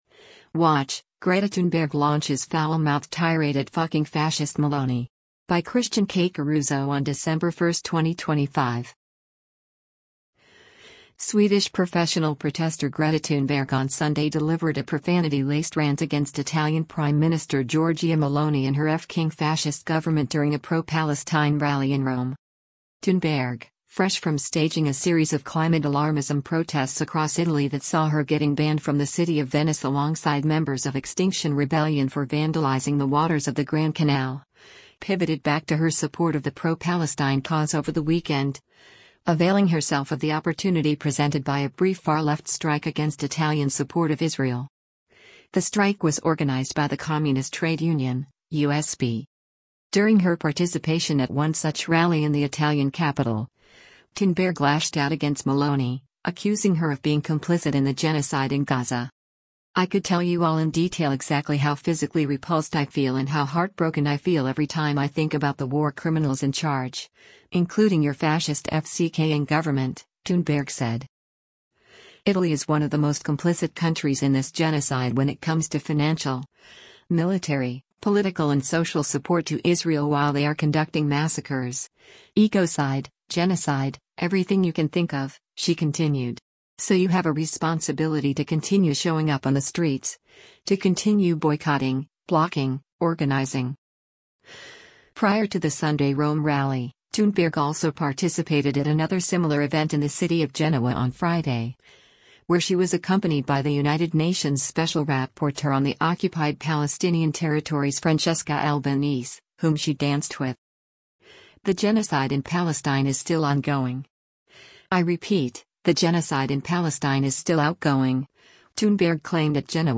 Swedish professional protester Greta Thunberg on Sunday delivered a profanity-laced rant against Italian Prime Minister Giorgia Meloni and her “f**king fascist” government during a pro-Palestine rally in Rome.